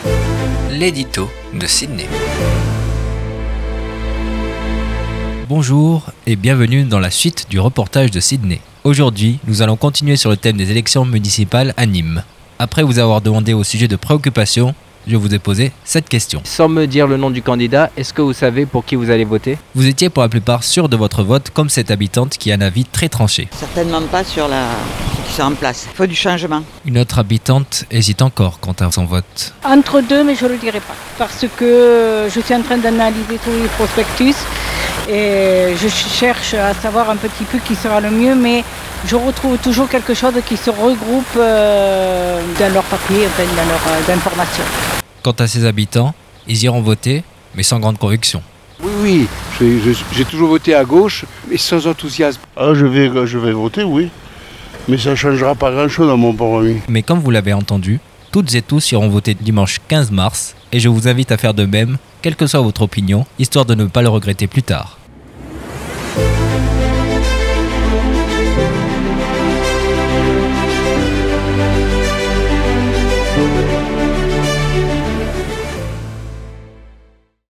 LA DÉCISION DE VOTE DES NÎMOISES ET DES NÎMOIS POUR LES ÉLECTIONS MUNICIPALES - L'EDITO